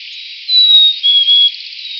"Continental" form of the Black-capped Chickadee song. Transliterates nicely as "hey-sweetie." "Sweetie" captures the extremely brief break in the second note.
bcch_co_song.wav